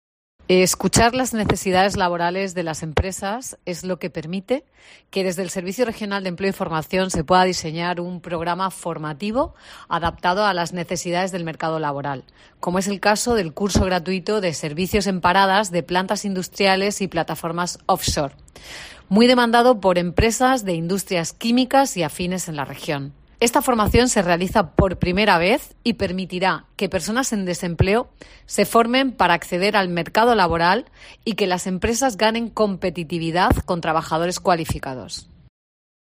Marisa López Aragón, directora general del SEF